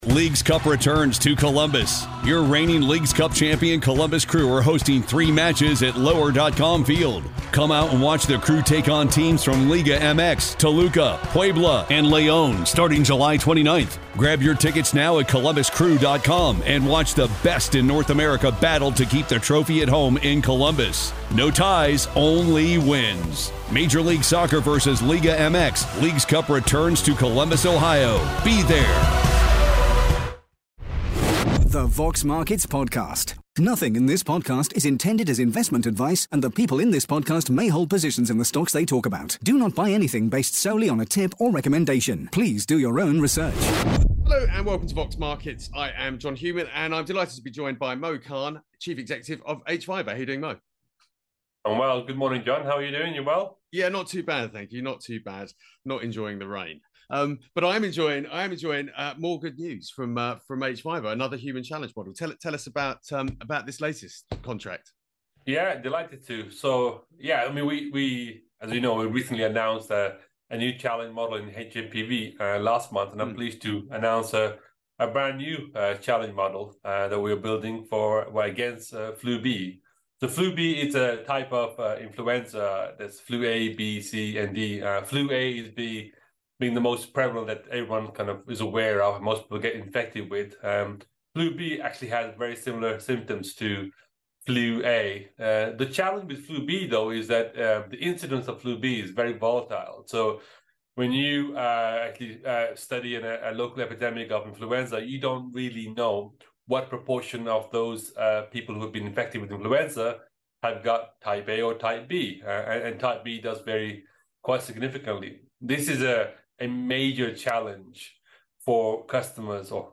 Vox Markets Interview